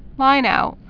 (līnout)